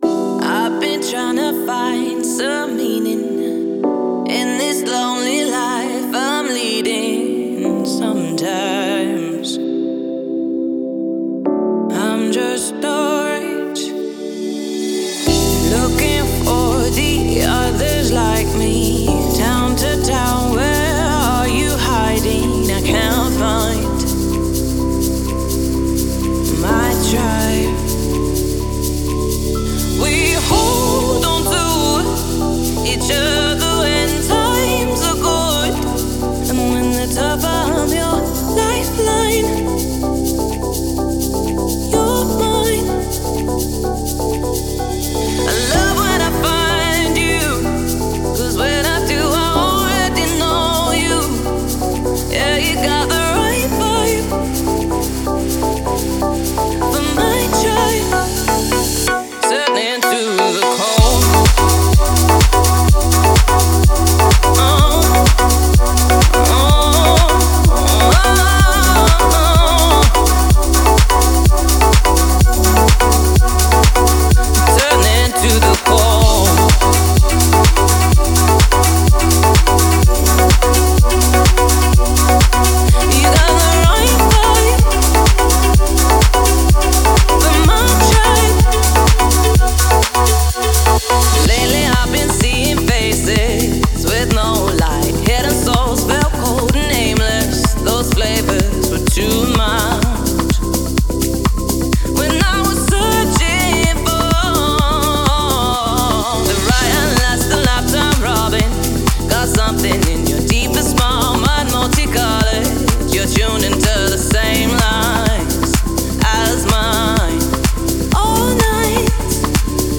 это трек в жанре электронный поп
атмосферные мелодии